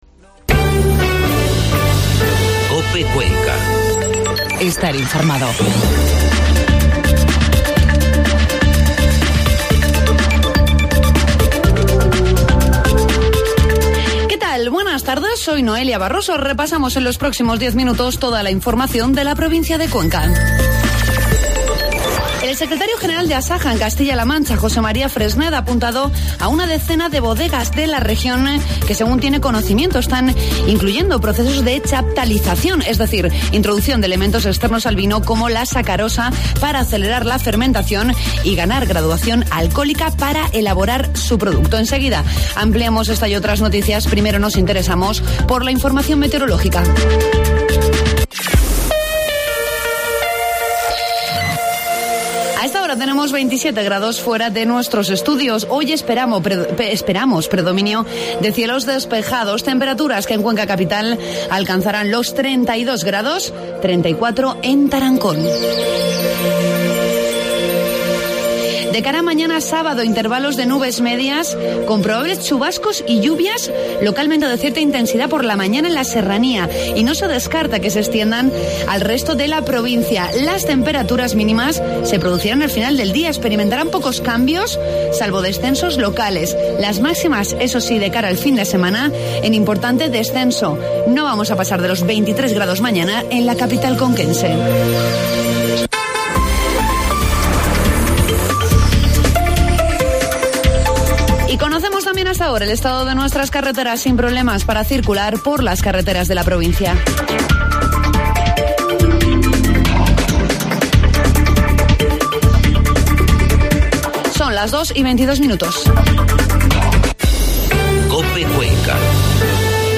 Informativo mediodía COPE Cuenca 8 de septiembre